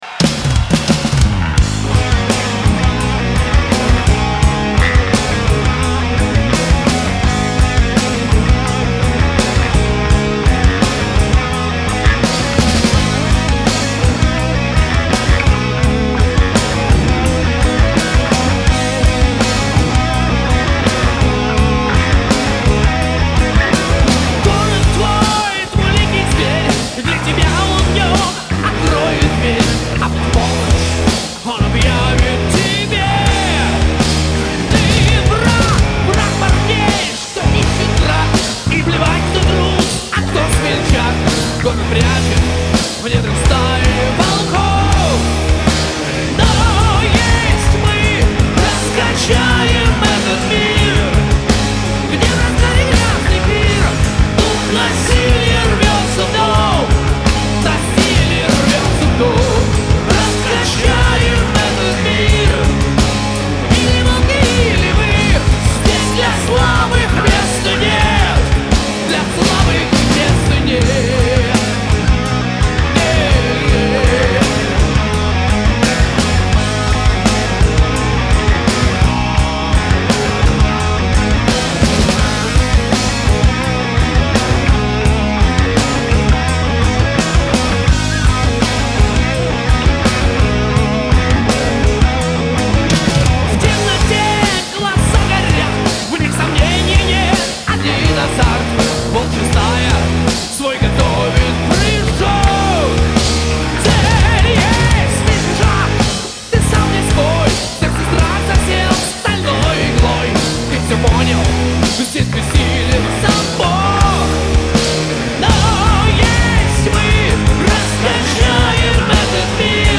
Жанр: Heavy Metal